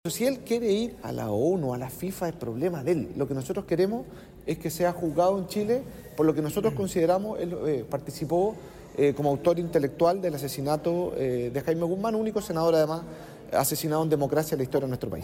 Al respecto, Coloma señaló que las instituciones ya hablaron y dijo que el abogado del exfrentista puede acudir donde quiera.